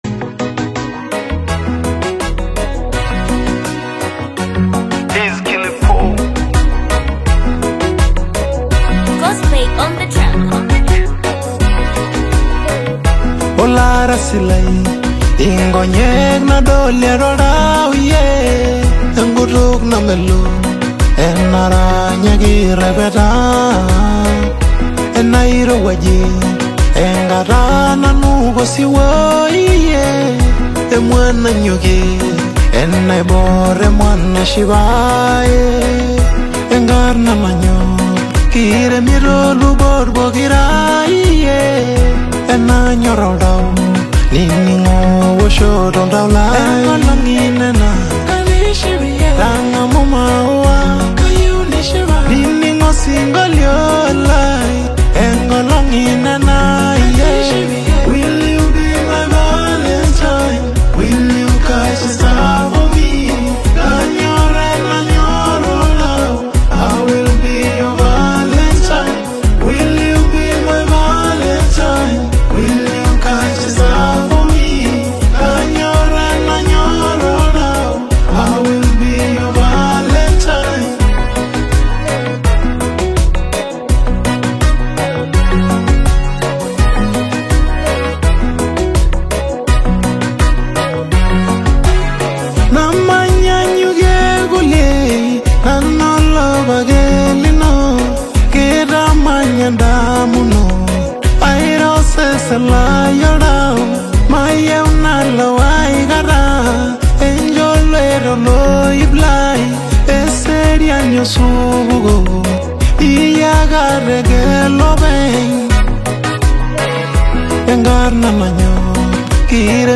AfrobeatAudioBongo flava
romantic Afro-Pop/R&B single
contemporary production that highlights his vocal charm